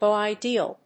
/bo ideal(米国英語)/